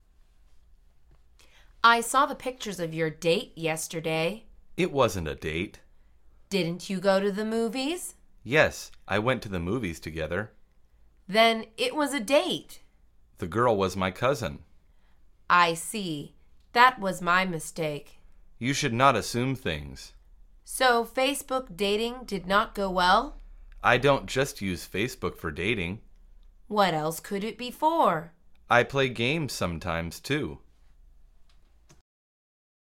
مجموعه مکالمات ساده و آسان انگلیسی – درس شماره ششم از فصل شبکه اجتماعی: عکس های فیس بوک